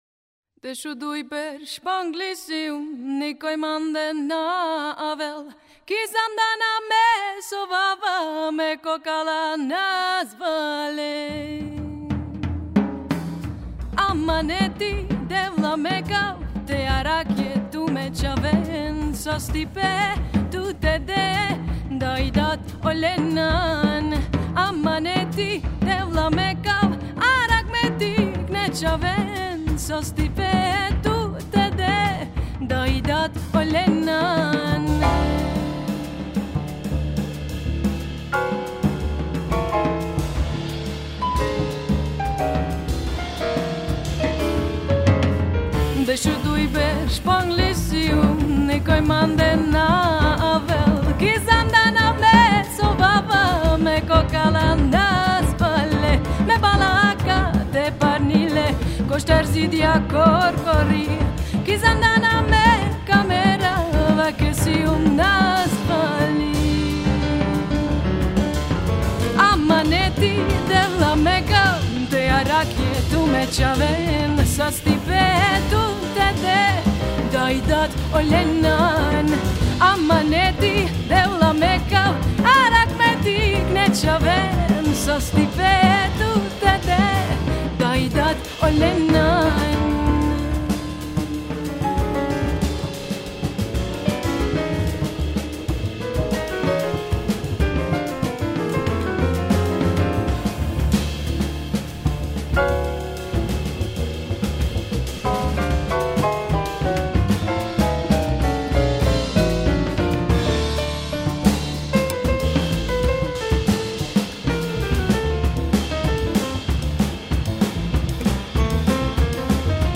Vocal / Balkan folk-jazz.